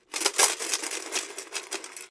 Monedas en una hucha
moneda
Sonidos: Hogar